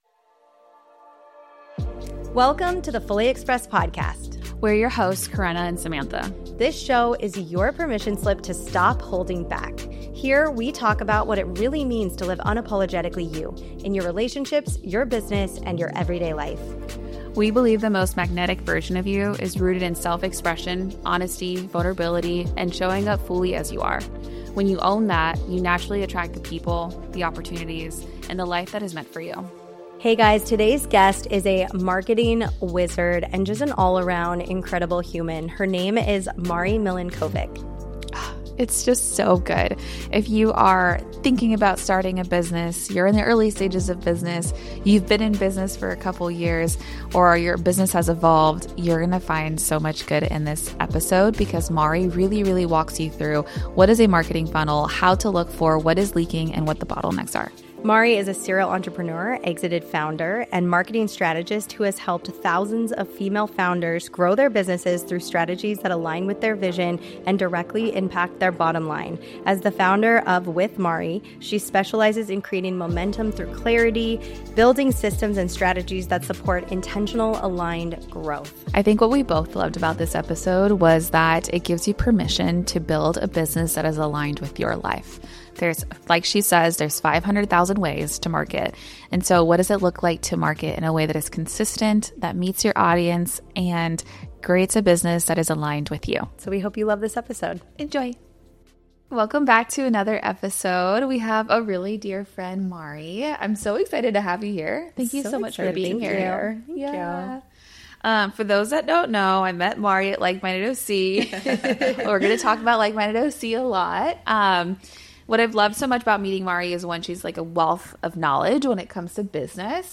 a refreshing, honest conversation about marketing